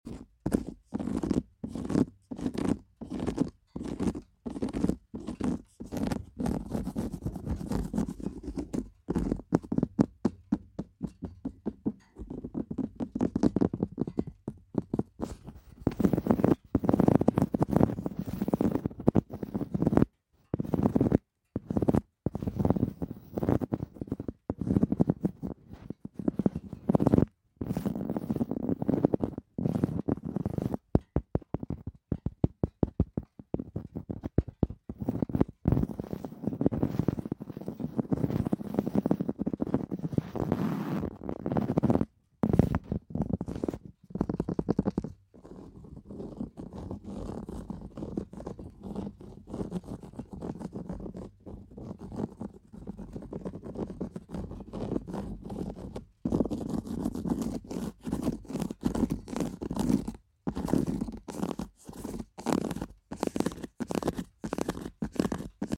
ASMR For all the sound effects free download